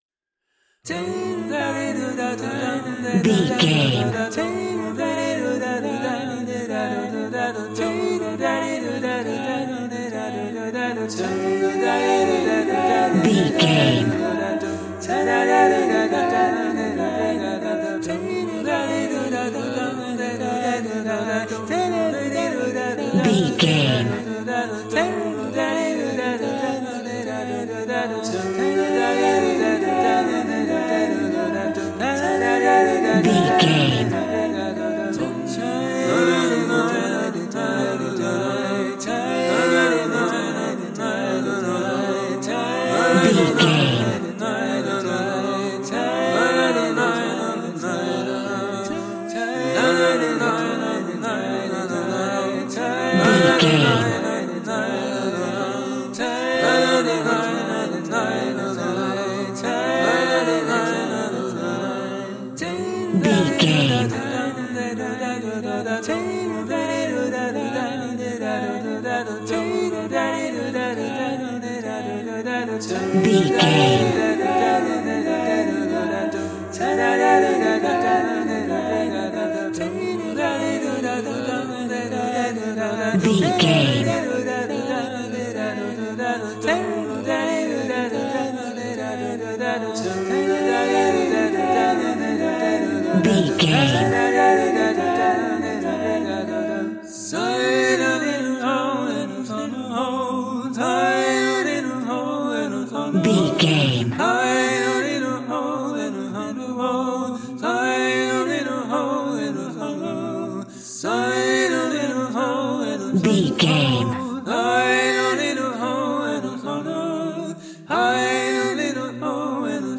Aeolian/Minor
cool
uplifting
bass guitar
electric guitar
drums
cheerful/happy